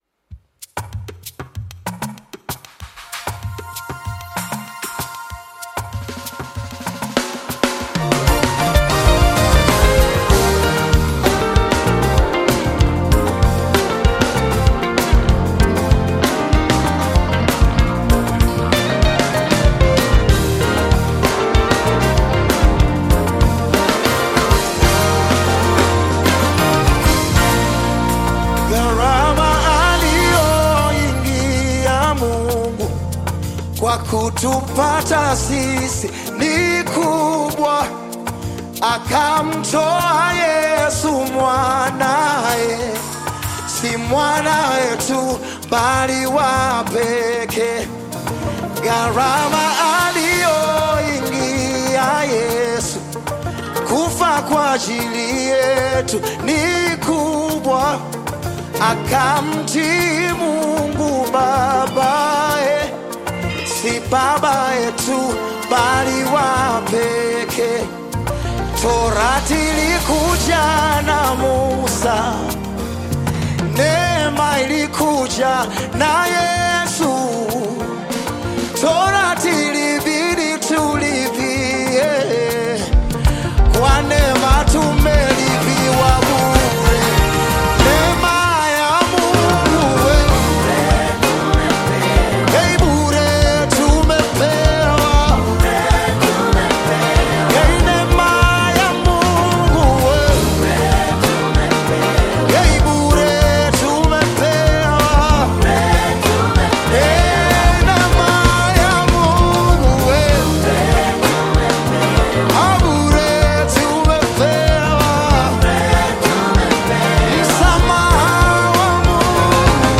Tanzanian Gospel
Gospel song